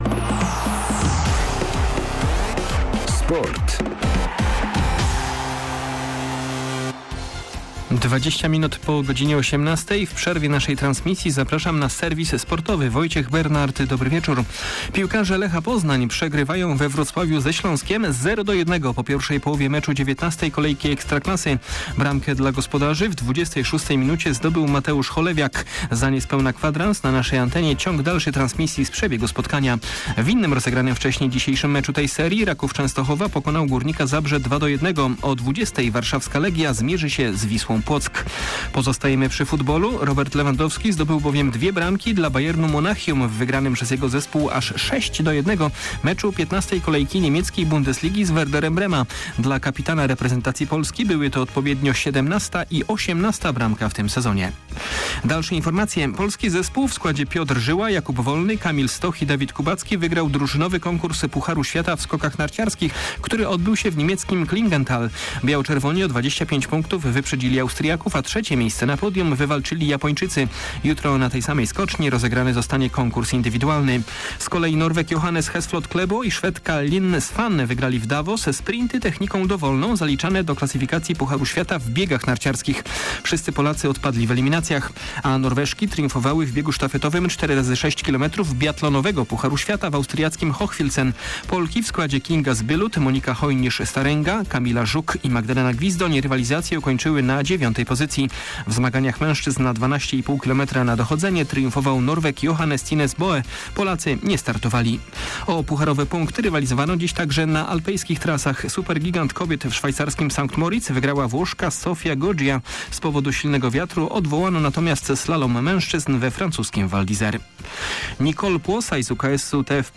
14.12. SERWIS SPORTOWY GODZ. 19:05